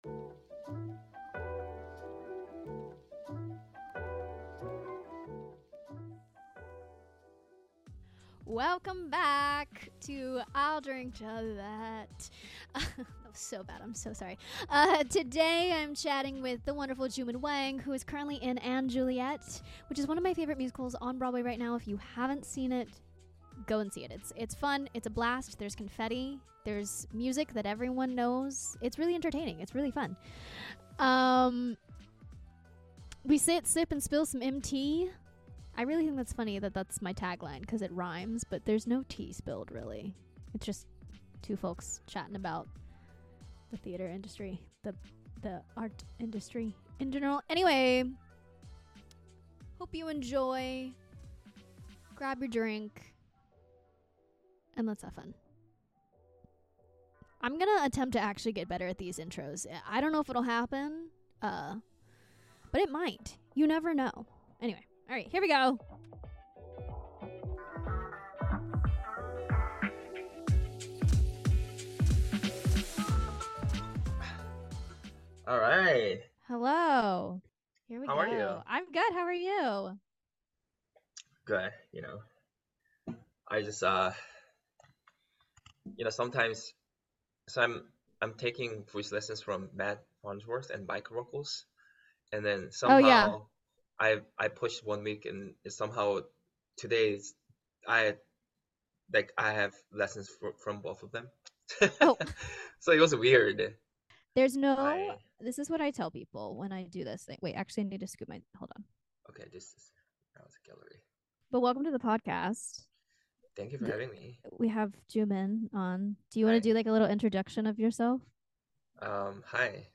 🎤 Shure SM58 with Focusrite